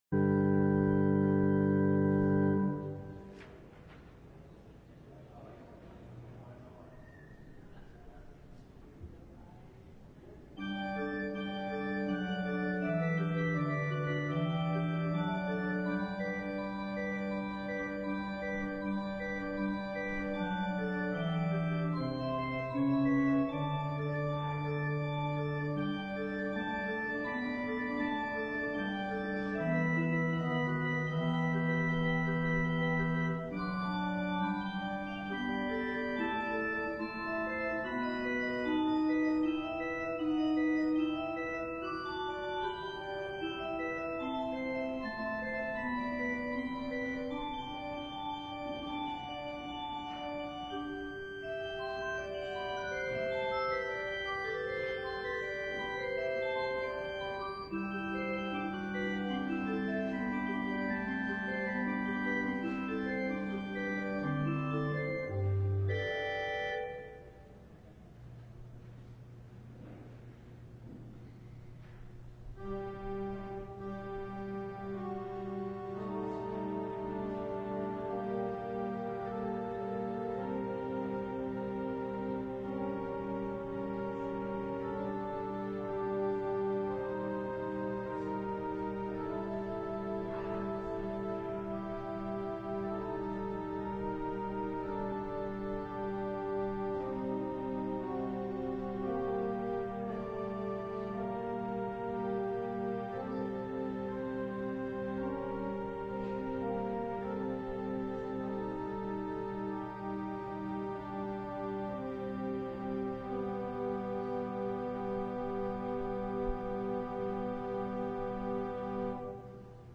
LIVE Evening Worship Service - Don’t Run On Ahead